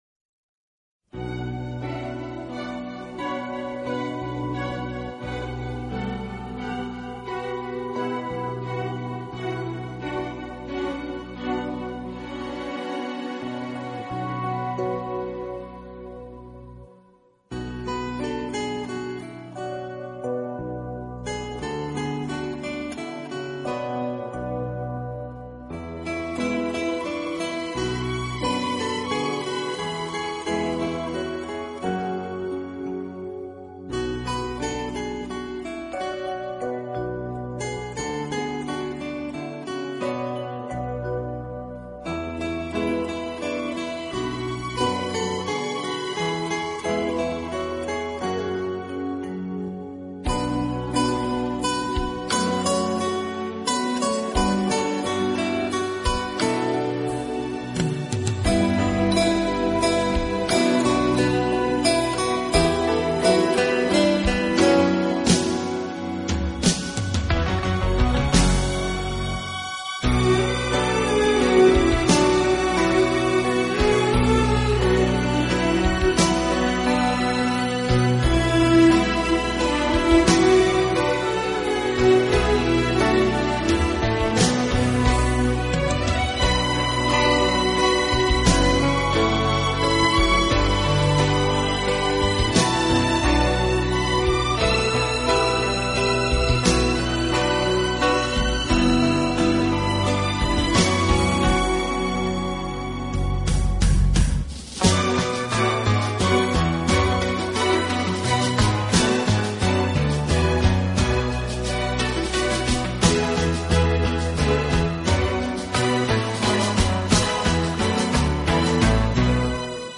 十二集超过二百首流行音乐元素与世界各地风情韵味完美结合的音乐，